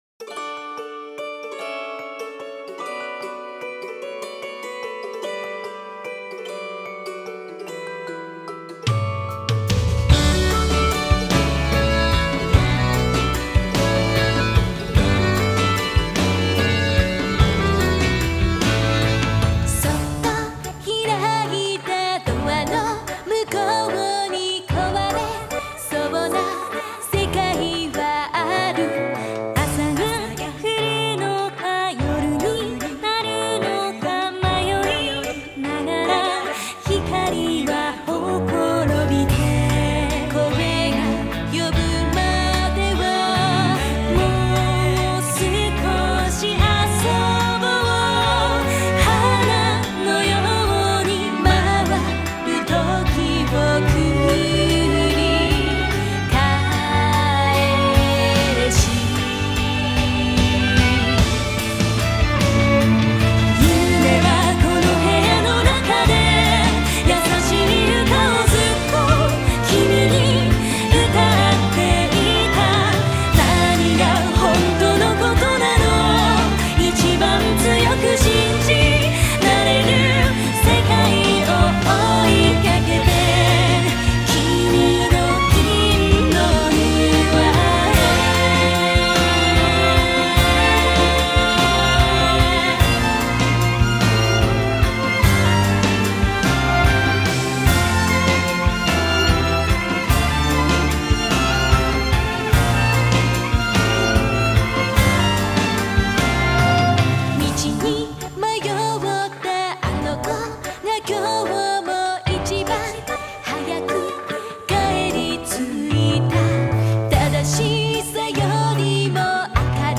Genre: J-Pop, Female Vocal